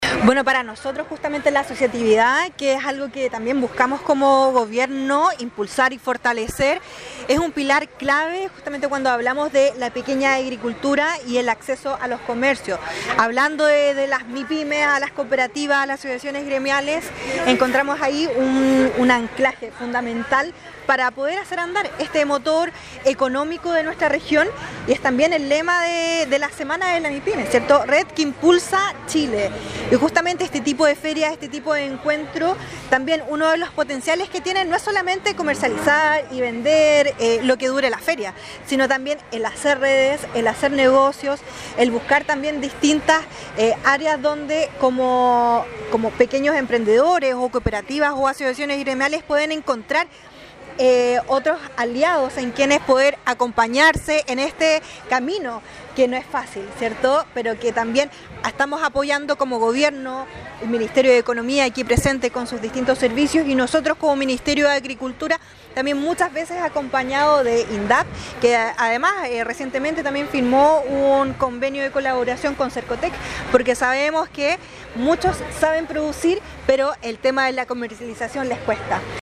La Semana de las Mipymes y Cooperativas es un lugar de encuentro para las empresas de menor tamaño y cooperativas del país, donde el diálogo público-privado es el protagonista, permitiendo que los y las emprendedoras se desarrollen y habiten este espacio en común, concluyó la Seremi de Agricultura, Tania Salas